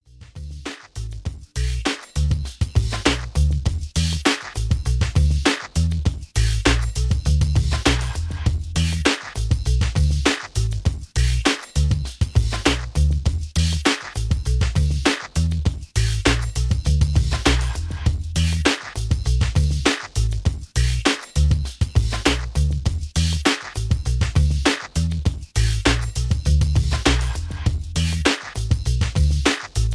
Background music suitable for TV/Film use.